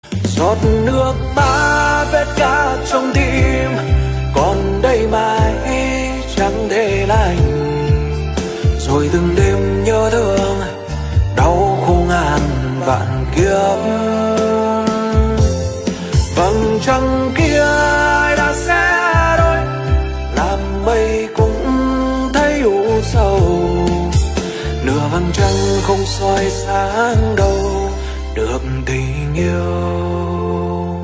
dòng nhạc Hoa Lời việt